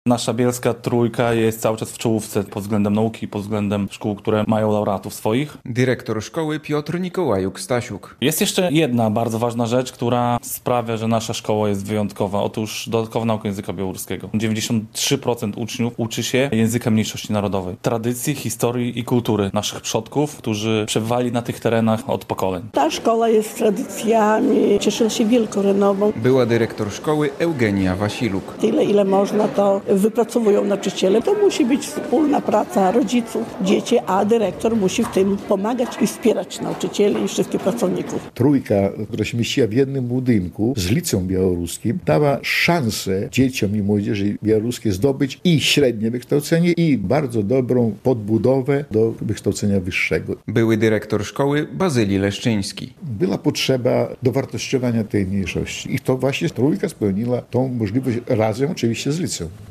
Szkoła, która uczy języka, tradycji i historii. Bielska "Trójka" świętuje 60-lecie - relacja